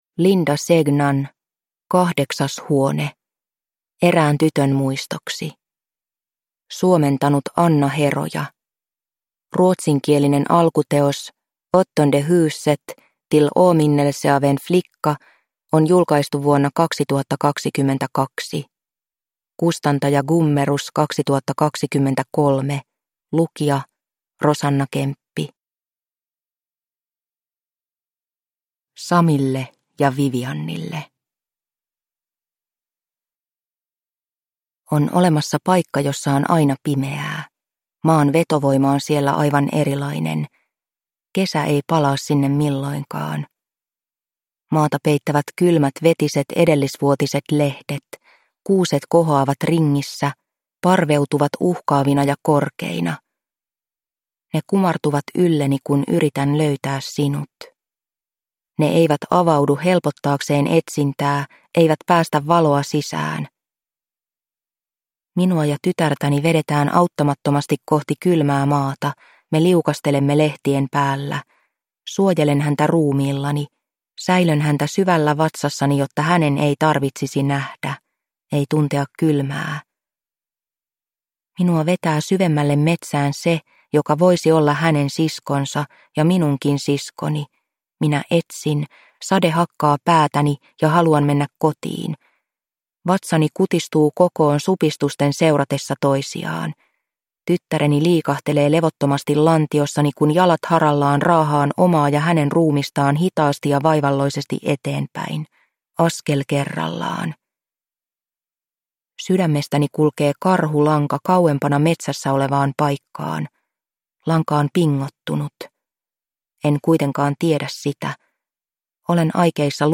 Kahdeksas huone – Ljudbok – Laddas ner